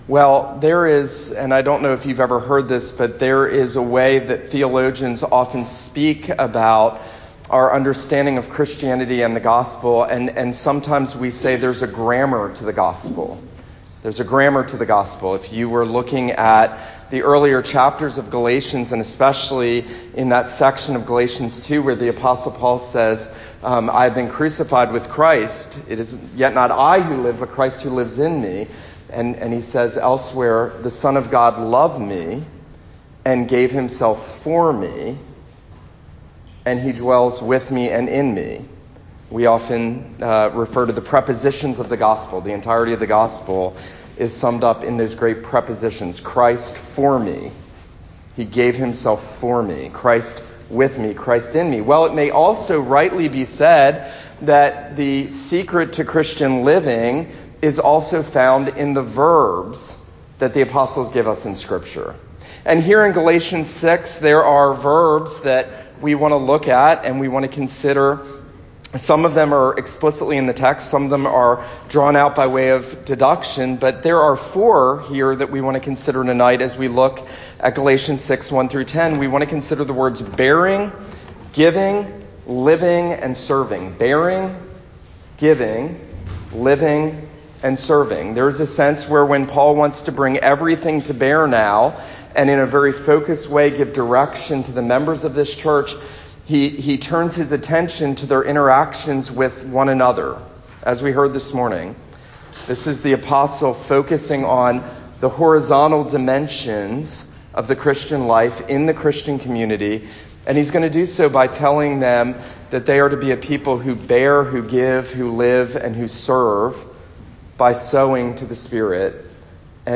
This is a sermon on Galatians 6:1-10.